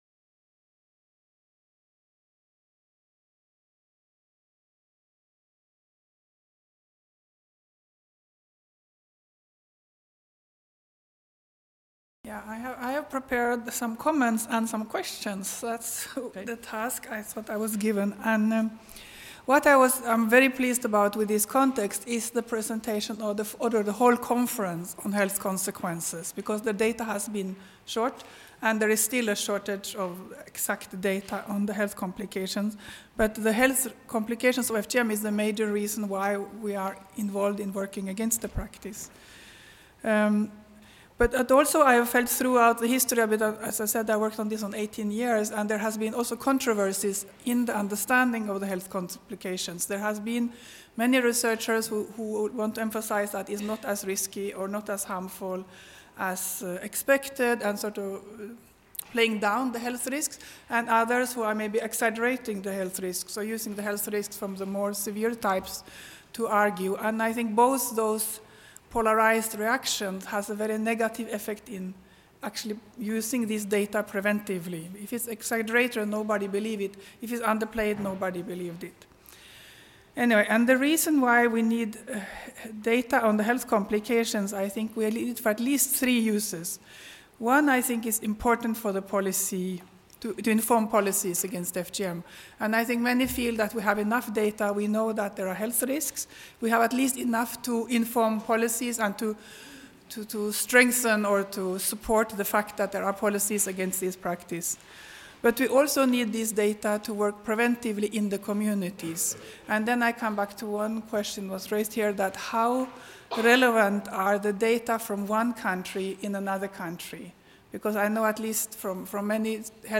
Discussion | Canal U
Norvège Débat avec le public